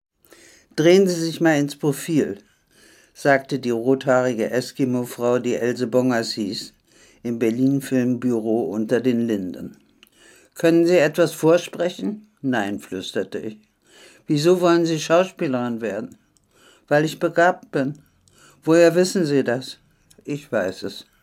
Im Dezember 1998 entstand im Berliner Heim der Künstlerin eine deutlich längere, leider noch längst nicht vollständige, Lesung.